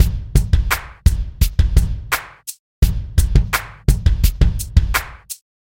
尤克里里琴...雷鬼乐章
描述：用吉他弹奏的E/A/B和弦进行曲（6弦乌克丽丽，类似吉他）。
标签： 循环 雷鬼 四弦琴
声道立体声